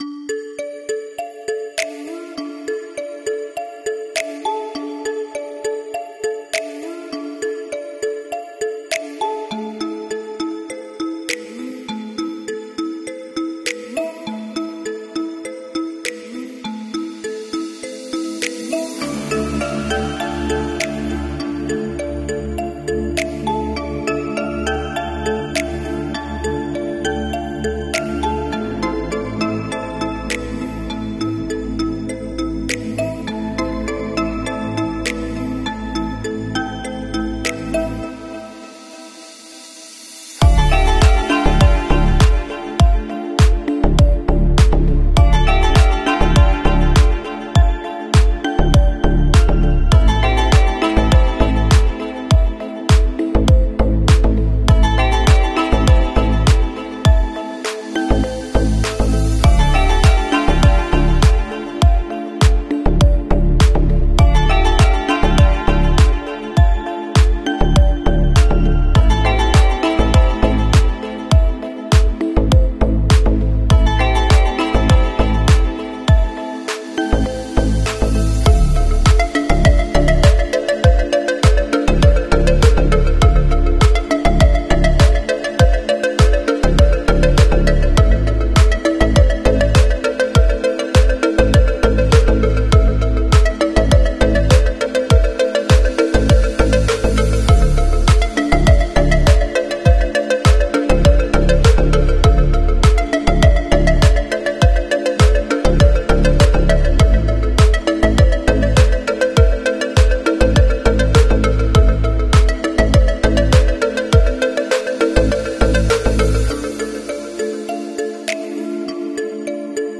Satisfying heart mosaic coloring 🎨💖ASMR